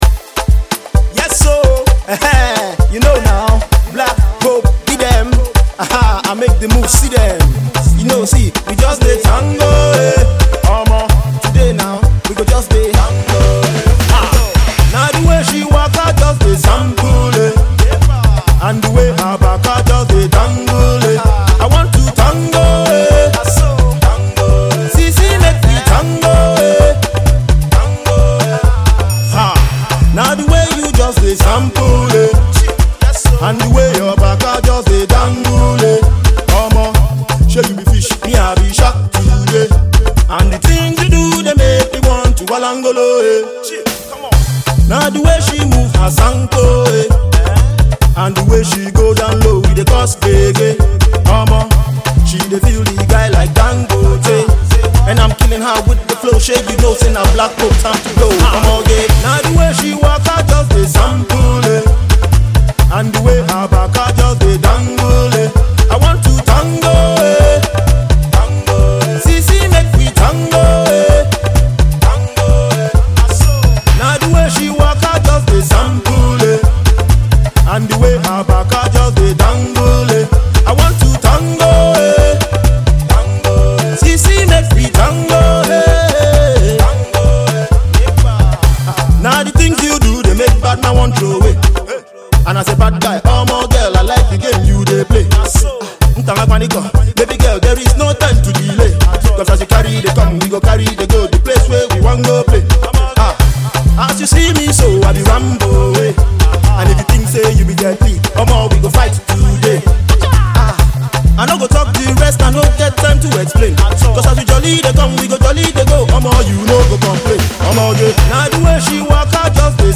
Its a Fast paced pop tune